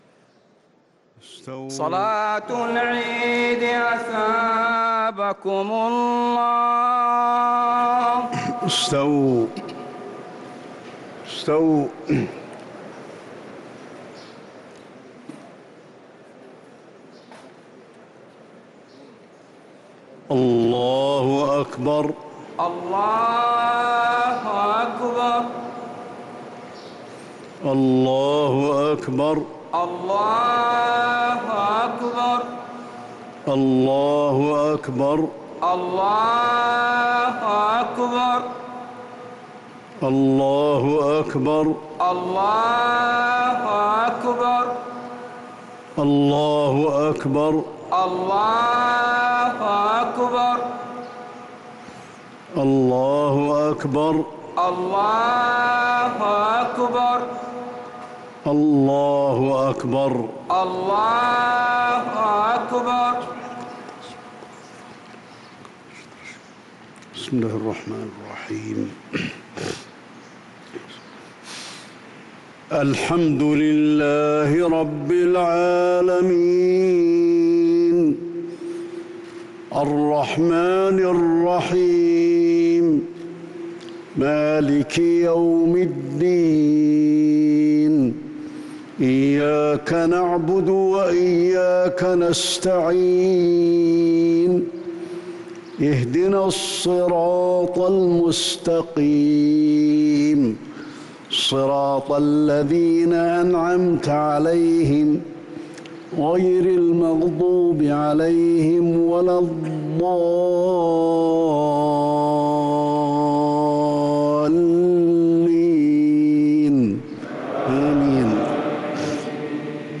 صلاة عيد الفطر سورتي الأعلى والغاشية ١شوال ١٤٤٣هـ | Eid prayers from Surat Al-A'la and Al-Ghashiyah 2-5-2022 > 1443 🕌 > الفروض - تلاوات الحرمين